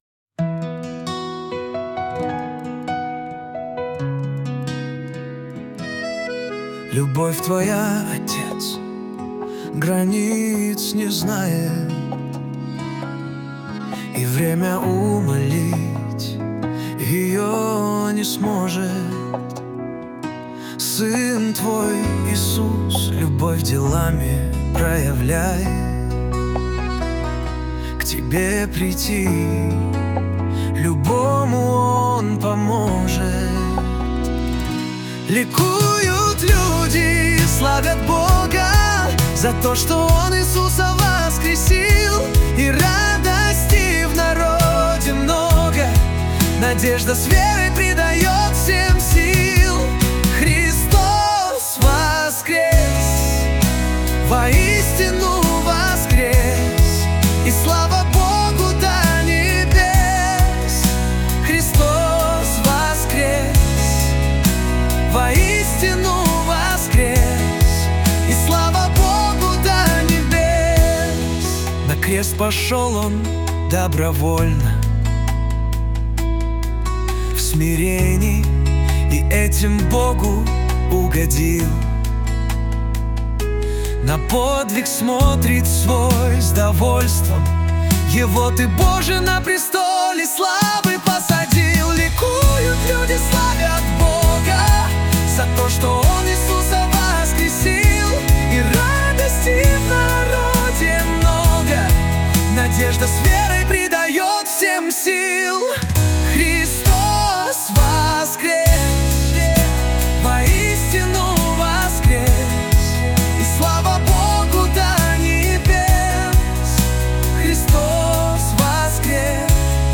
песня ai
211 просмотров 1211 прослушиваний 78 скачиваний BPM: 68